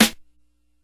Snare (Frontin').wav